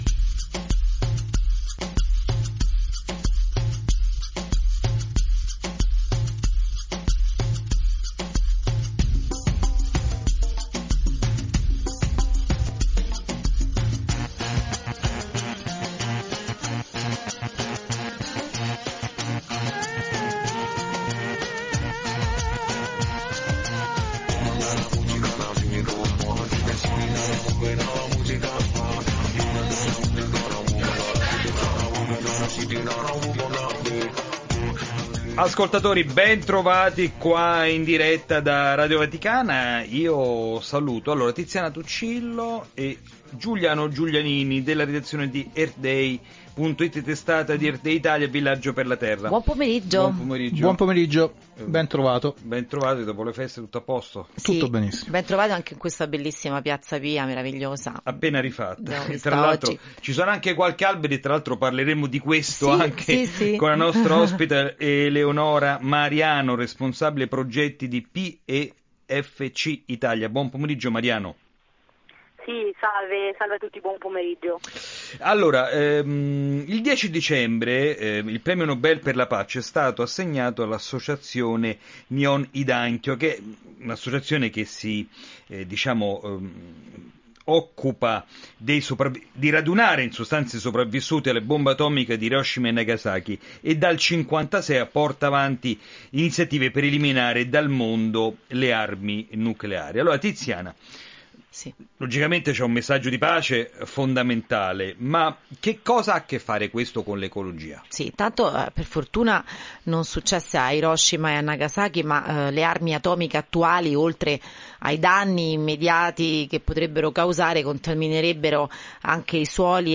la rubrica radiofonica settimanale curata da Earth Day Italia